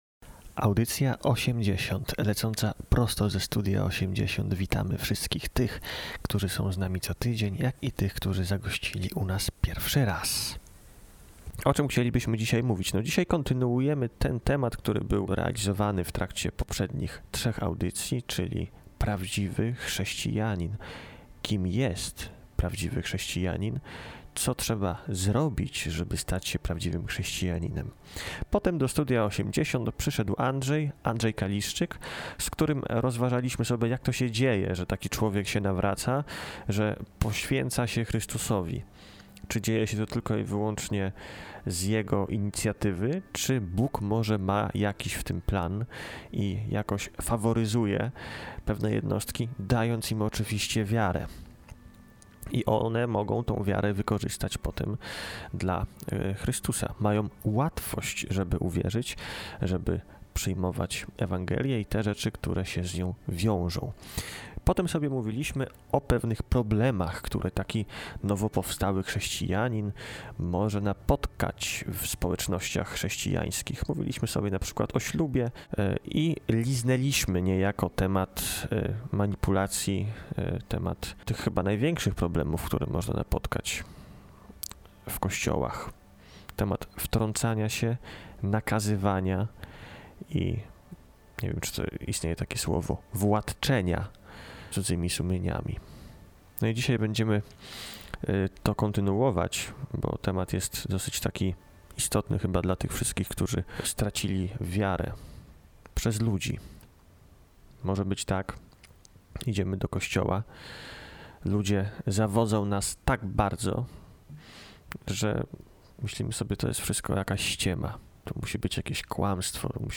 Każda audycja obfituje w masę niekonwencjonalnej muzyki, granej przez wyjątkowych, aczkolwiek bardzo często niszowych artystów.